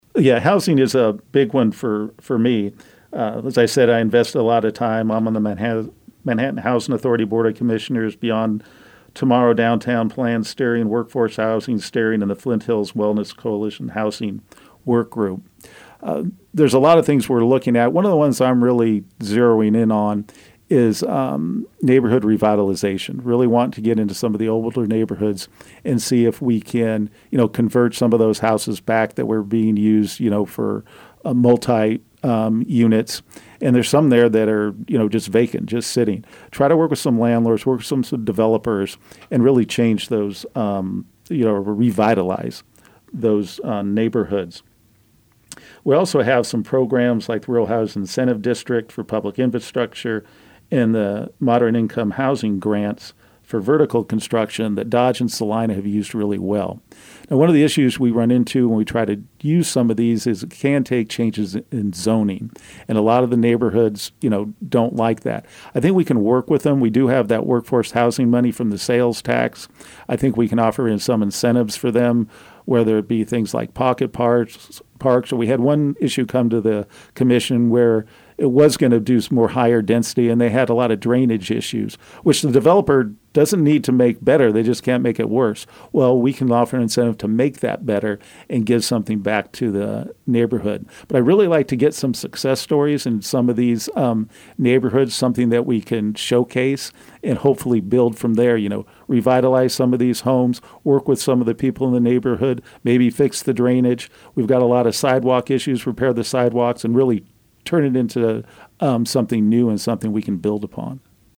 News Radio KMAN has invited candidates seeking a seat on the Manhattan City Commission and Manhattan-Ogden USD 383 school board to be interviewed ahead of Election Day.